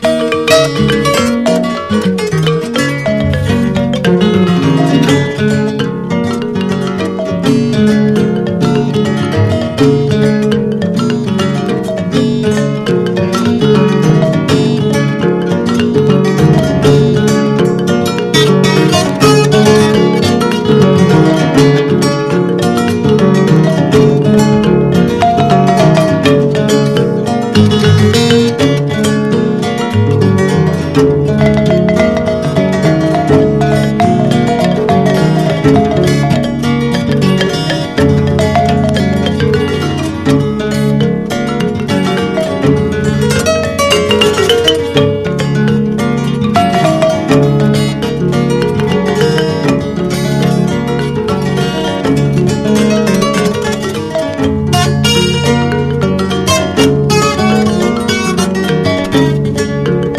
WORLD / OTHER / FRENCH / BRAZILIAN
フレンチ・ブラジリアン最高峰！